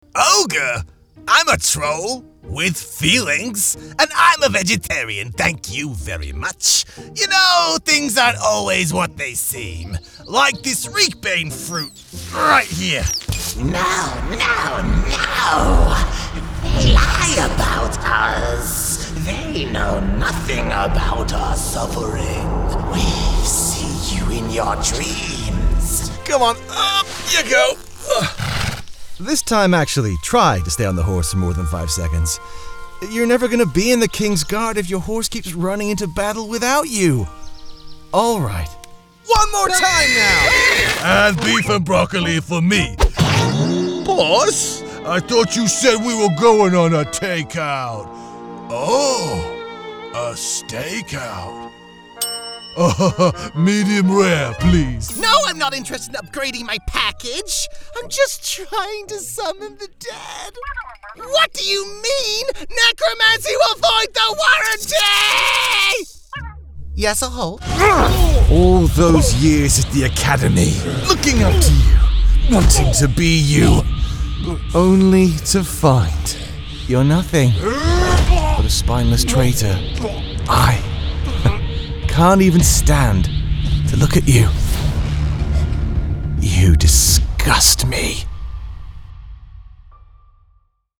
If you’re looking for a smooth British voice as well as other accents (If that’s your cup of tea), look no further.
british rp | character
standard british | natural
ANIMATION 🎬